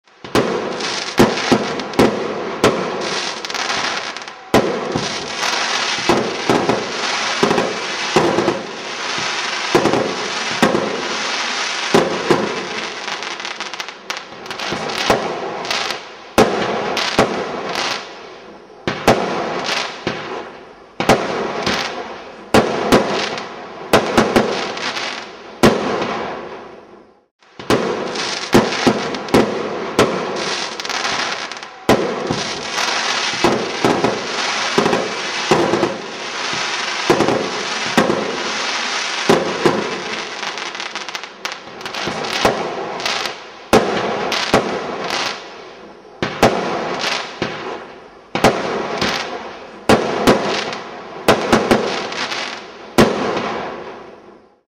салют